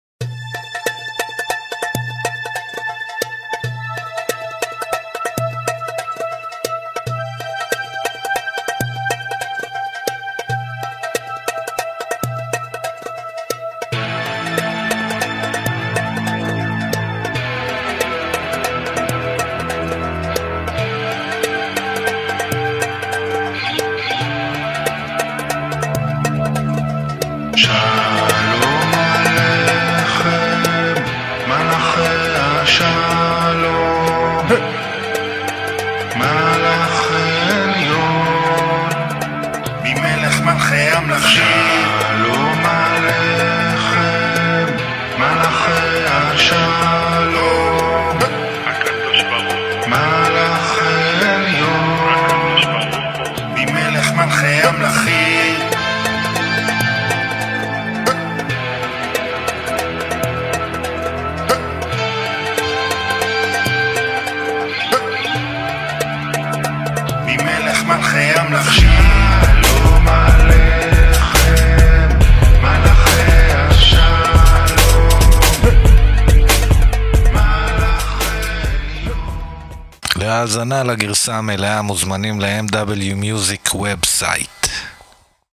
הרכב רוק־אינדי עברי חד, חצוף ועמוק